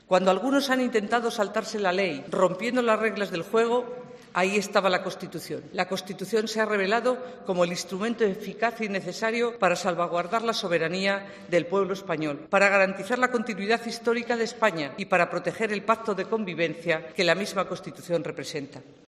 En su discurso en la recepción oficial en el Congreso con motivo del Día de la Constitución y sin citar expresamente la crisis en Cataluña, Pastor ha defendido la ley como la "guardiana de nuestra seguridad" y el "freno" contra el intento de cualquiera de someter a los ciudadanos "arbitrariamente a sus deseos".